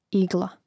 ìgla igla